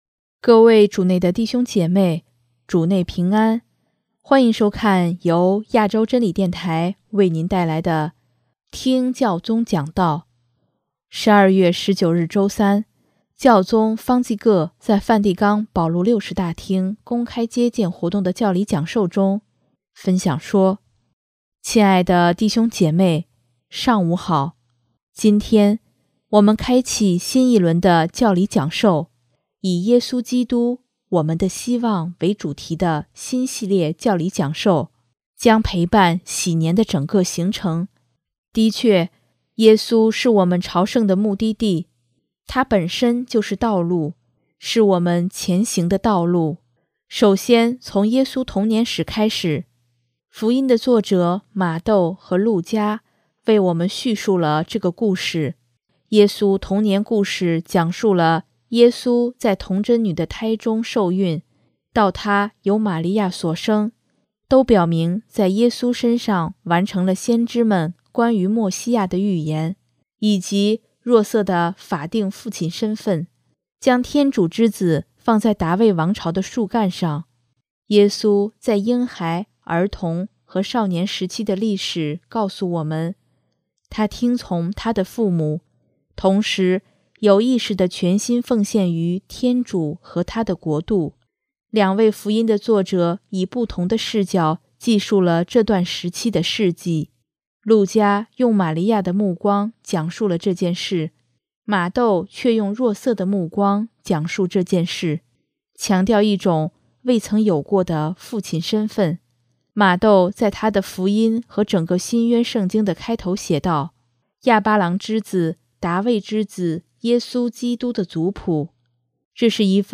12月19日周三，教宗方济各在梵蒂冈保禄六世大厅公开接见活动的教理讲授中，分享说：